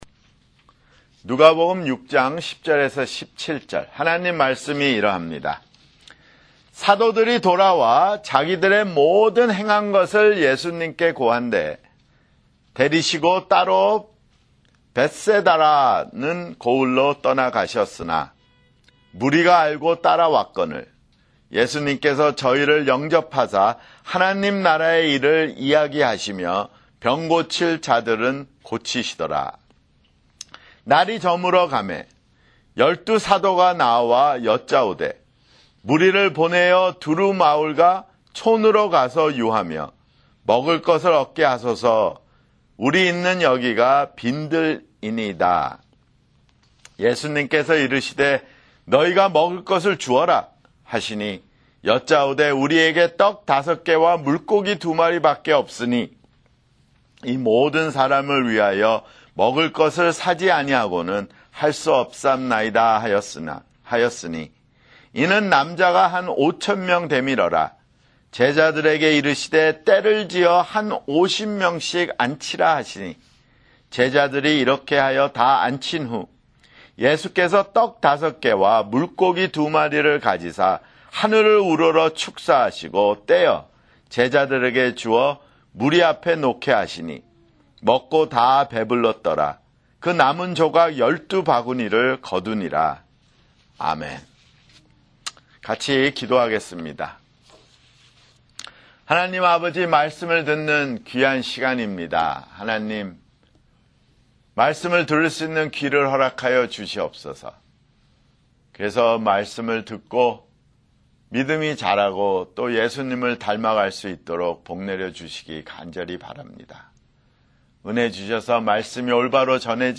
[주일설교] 누가복음 (62)